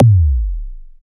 Index of /90_sSampleCDs/Optical Media International - Sonic Images Library/SI2_SI FX Vol 2/SI2_Gated FX 2
SI2 FM BOOM.wav